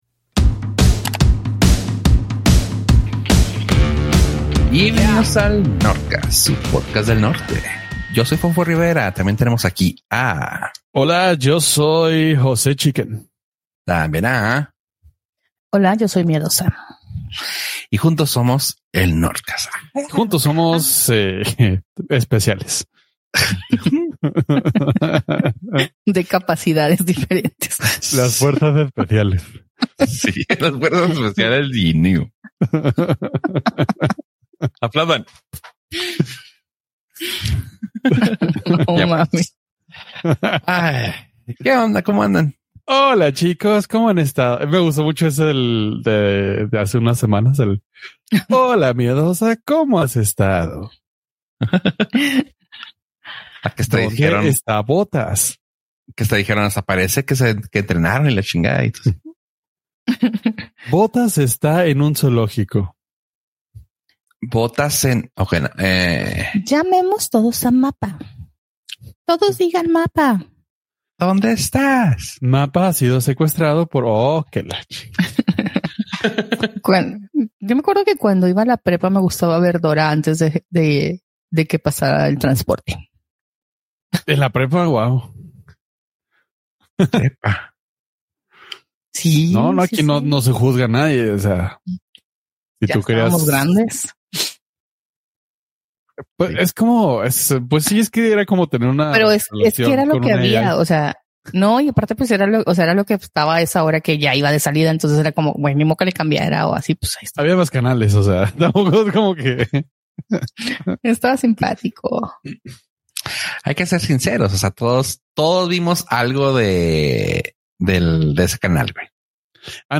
Entre risas, corajes por managers mediocres y anécdotas de la infancia (Barney incluido 🤫), descubrimos que sí, la comida rápida es cultura pop, pero nada le gana a un buen burrito de la esquina